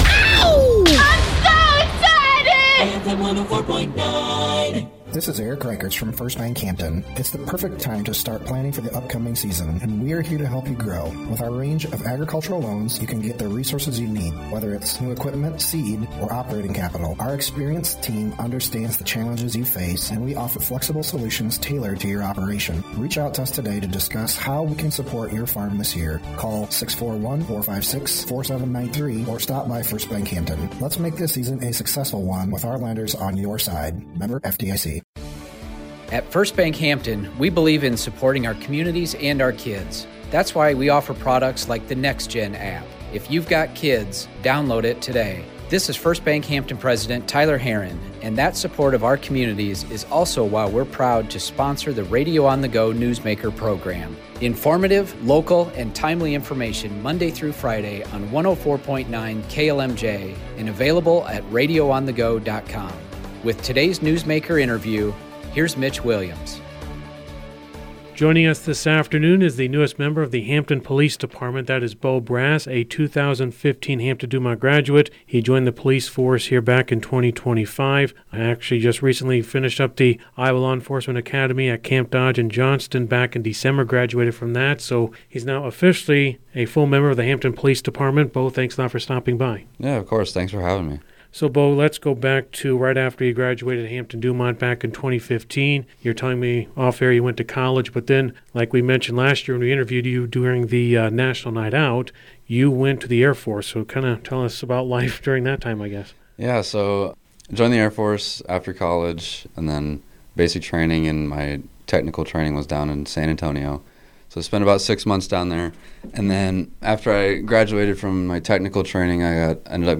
Full interview here